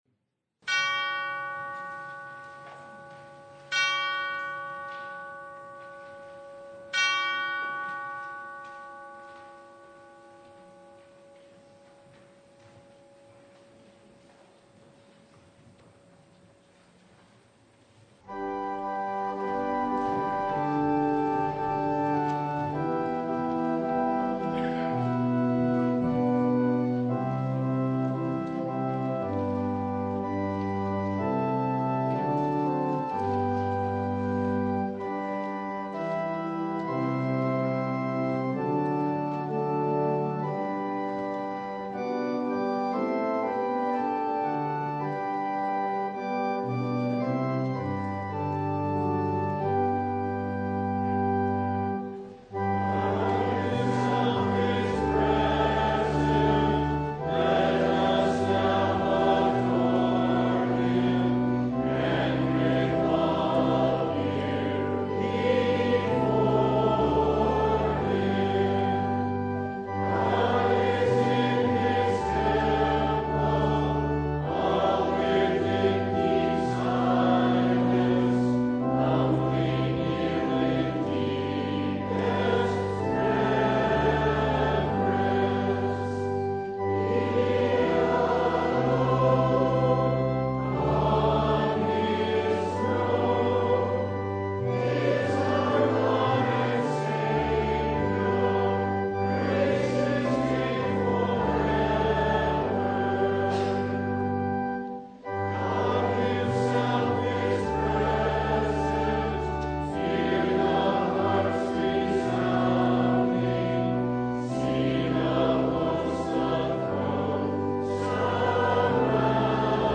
Luke 14:1-14 Service Type: Sunday Pride is the original sin—from Satan to Adam and Eve to us.